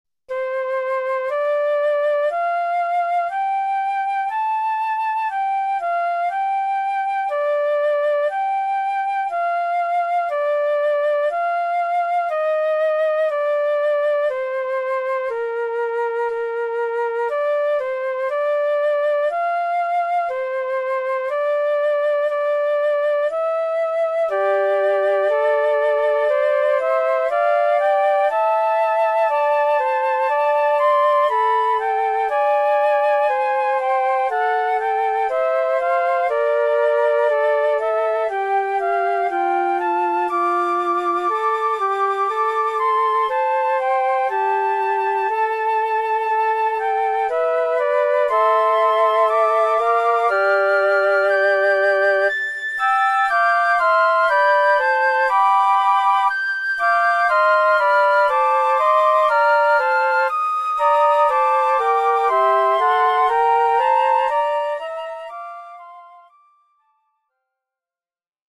Besetzung 4 Querfl�ten